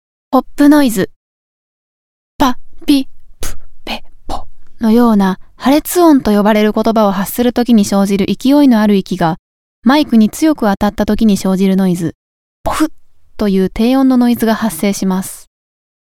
ノイズを大きく軽減するオプションサービスを行っています。
■ ポップノイズを取ってみた
03_Pop-Noise_Voice-Cleaning.mp3